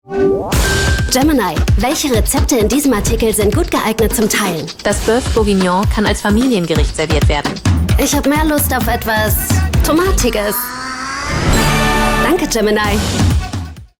sehr variabel, markant, hell, fein, zart, plakativ
Jung (18-30)
Commercial (Werbung)